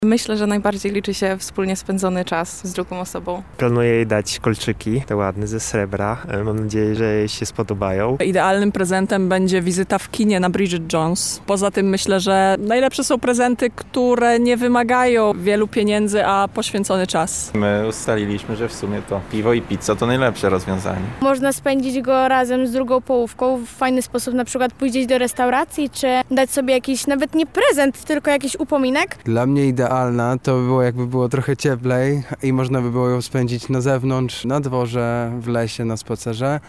Zapytaliśmy lubelskich studentów, jakie mają plany w związku z tym świętem i co według nich liczy się tego dnia najbardziej:
SONDA